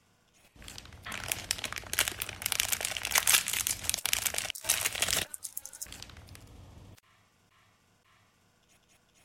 Tiếng Xé túi mù, Mở bao ni lông…
Tiếng Xé túi giấy, Mở bao giấy… Tiếng Xé túi mù, Xé bao… (Ngắn)
Thể loại: Tiếng động
Description: Âm vang rọc rẹc, sột soạt, lạo xạo, xoạt xoạt, mô phỏng hành động xé hoặc mở các loại túi nhựa, bao nilon. Đây là một dạng sound effect dựng phim, ASMR, hiệu ứng foley.... Âm thanh này thường có độ khô, giòn, tiếng ma sát và tiếng rách của chất liệu nhựa mỏng khi bị kéo căng hoặc xé ra.
tieng-xe-tui-mu-mo-bao-ni-long-www_tiengdong_com.mp3